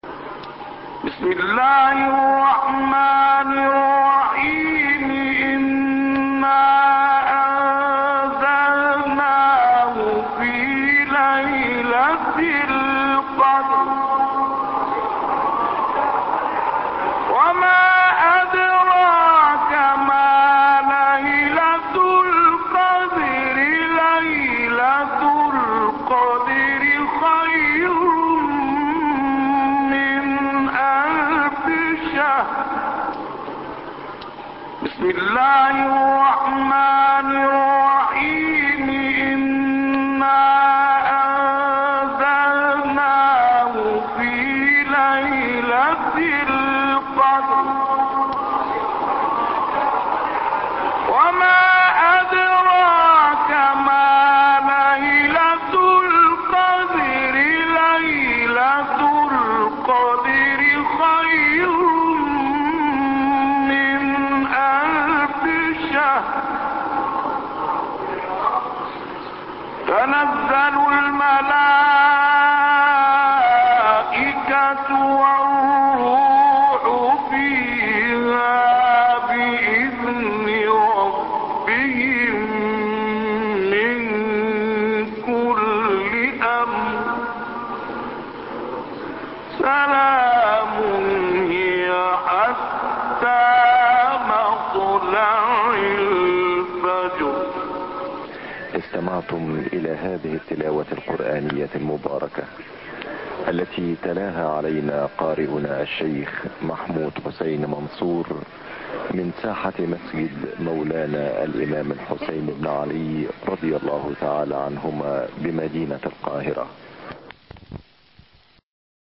مقام النهاوند